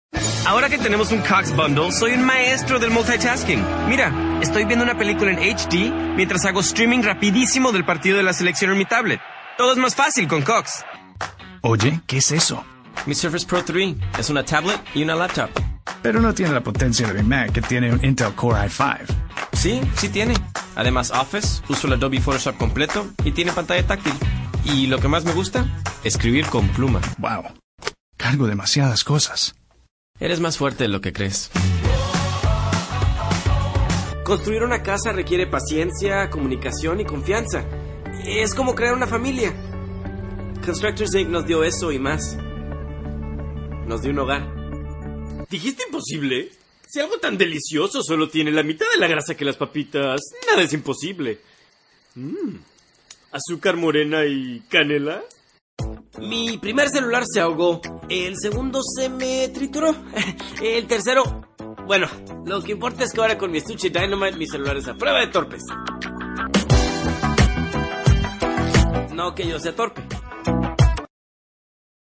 English Demo